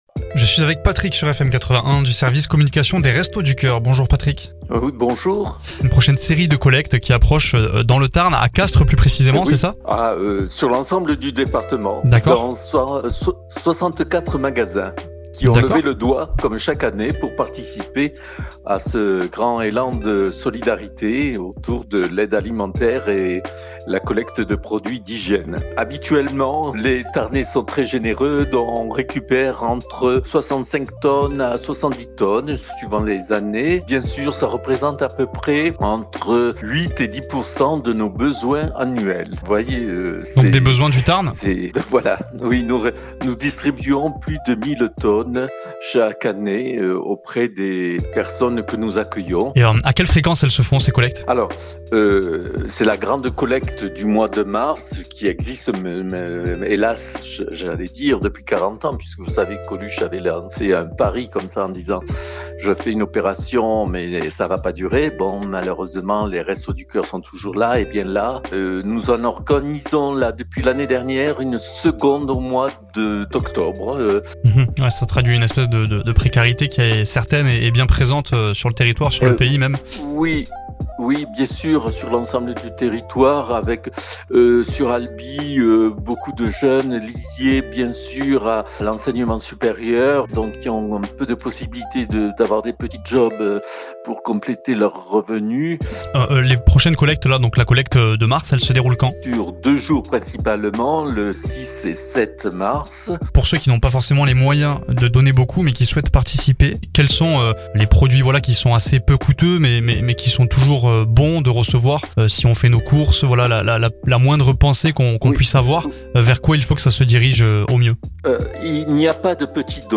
FM81, radio proposant de l’info loisir du Tarn et tous les standards français des années 60, 70 et 80